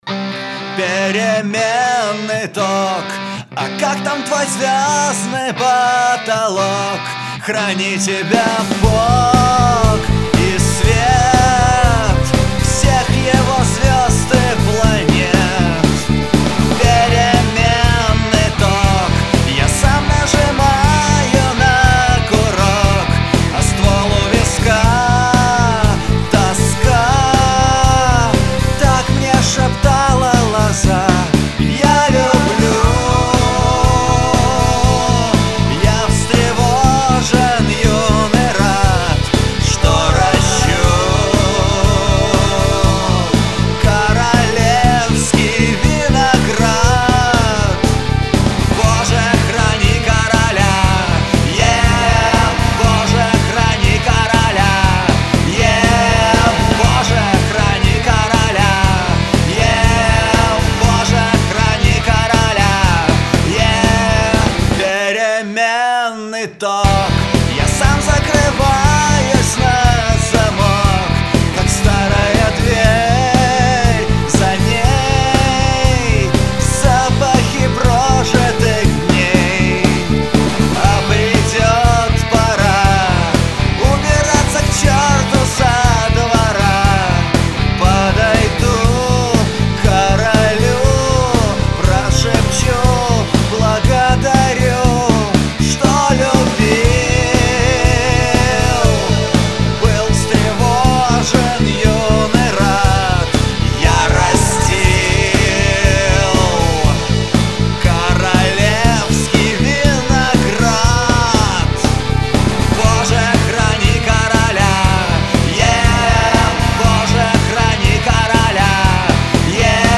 Альбом записан на домашней студии.